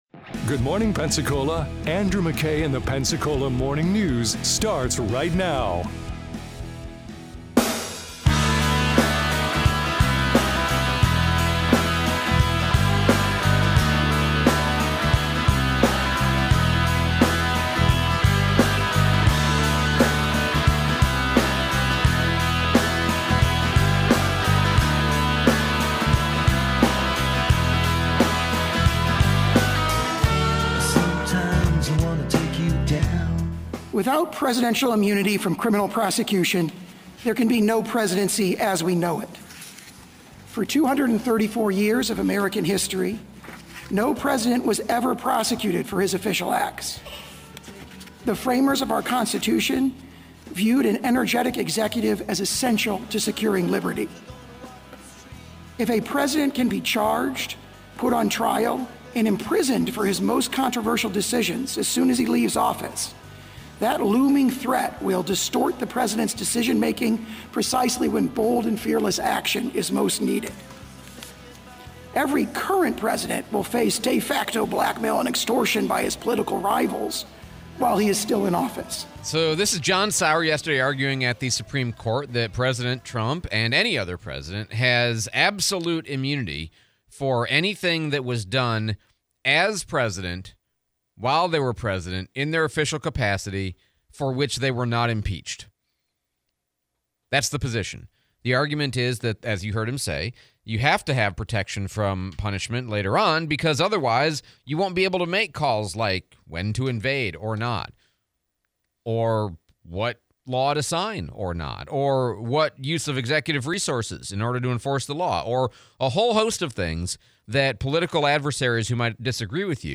For what can the US President be tried? / Replay of interview